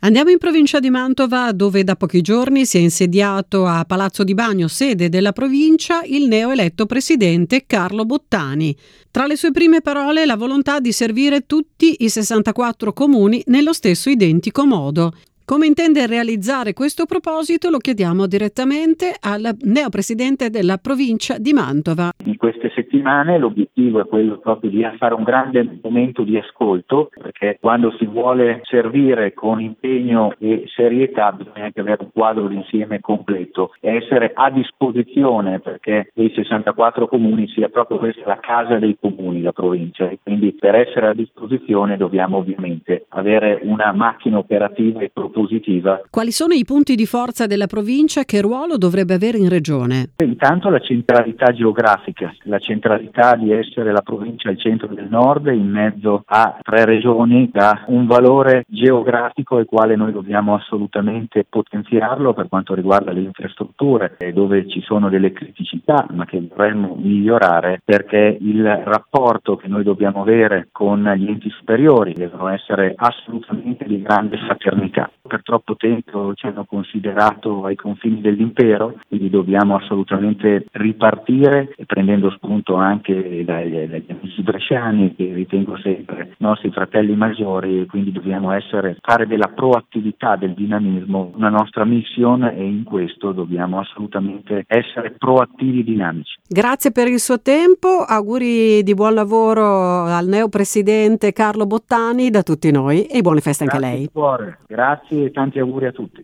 24/12/2021: Le parole del neo presidente della Provincia di Mantova, Carlo Bottani, eletto sabato 18 dicembre: